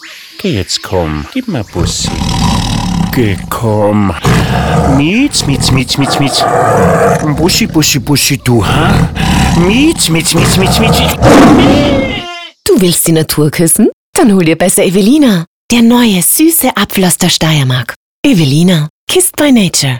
Neuer Evelina Radiospot